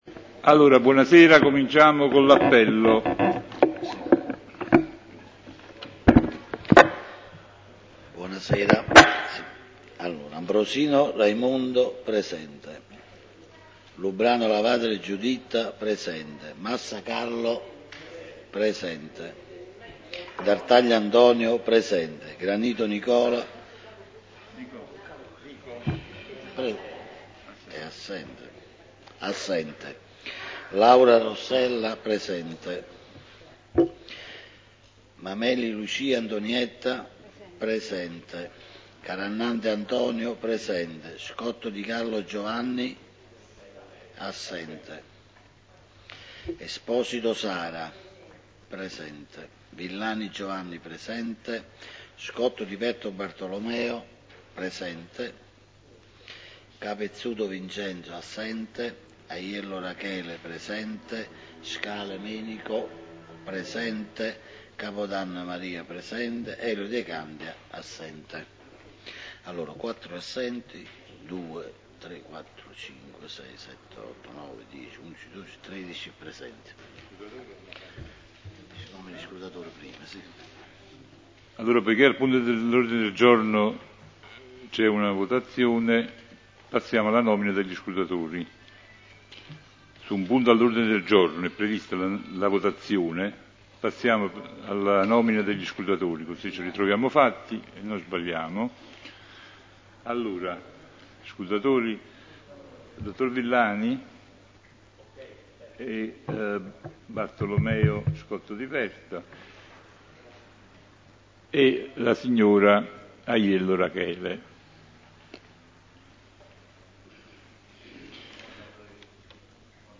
Pubblicato nella sezione Radio di questo il Consiglio Comunale del 28 agosto 2015 tenutosi presso la sala “V. Parascandola” del Comune in via Libertà.